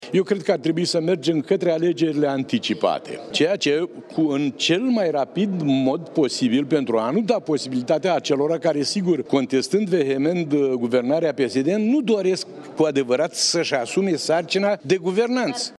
Președintele PSD Vaslui, Dumitru Buzatu: ”Eu sunt adeptul celei de-a treia soluții, în sensul că există un grad foarte mare de contestare din partea opoziției, există o opoziție netă și fățișă din partea președintelui Iohannis față de guvernarea PSD, există și un vot popular la care e foarte greu să decelăm cu exactitate cât a fost la europarlamentare și cât a fost dat împotriva petrsoane Dragnea.